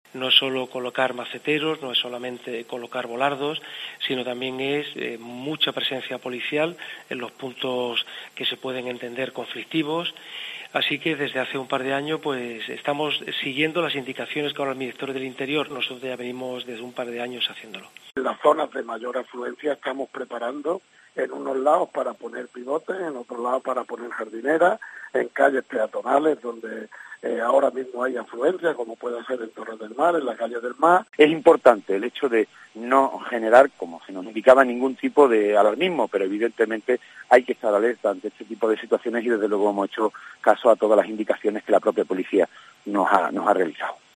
El alcalde de Estepona y Vélez-Málaga y el concejal de Obras de Ronda sobre las medidas